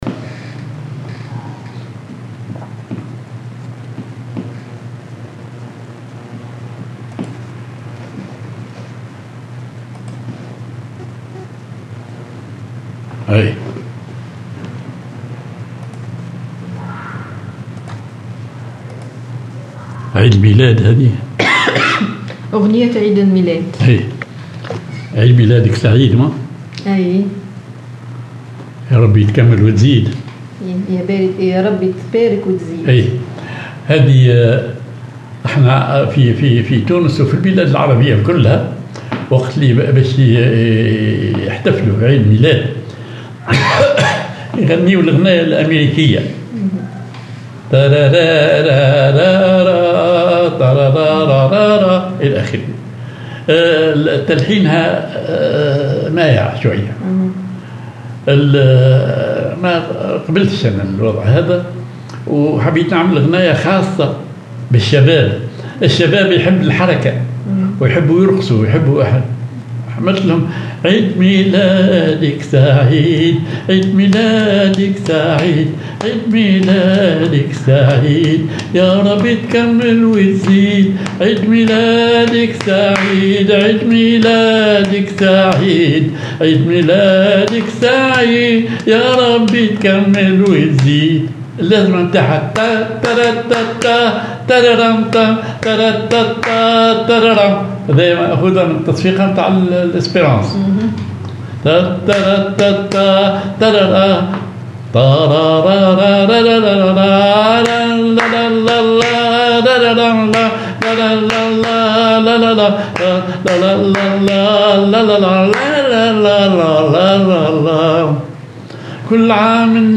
Maqam ar حجاز